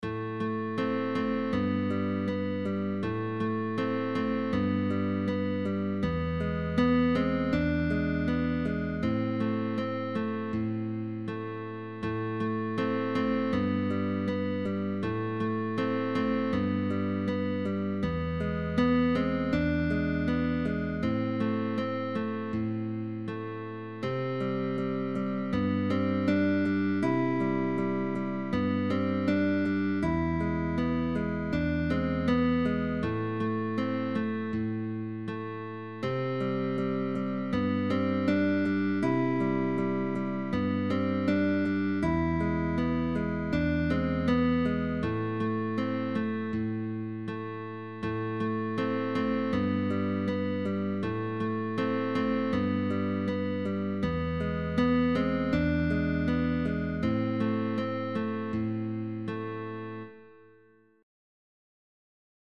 Very, very Easy.